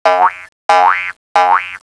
SpringzMoving.wav